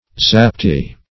Zaptiah \Zap"ti*ah\, n. A Turkish policeman.